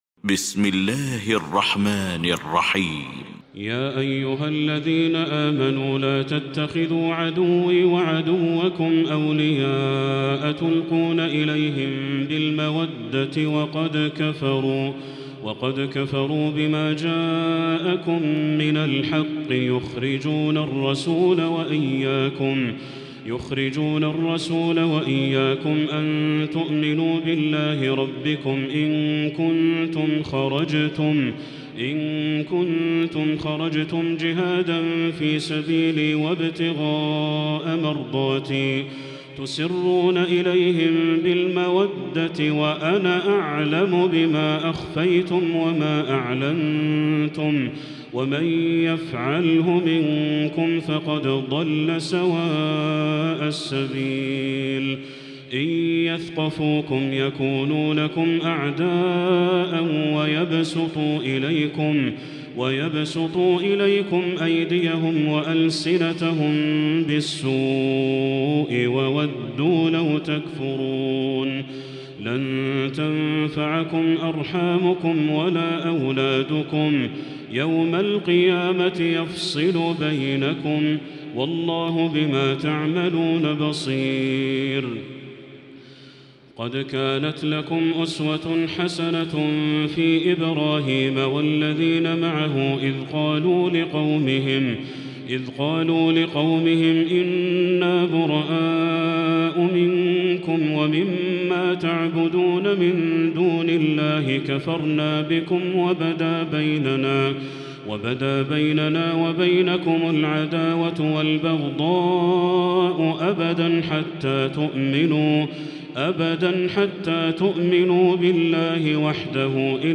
المكان: المسجد الحرام الشيخ: بدر التركي بدر التركي الممتحنة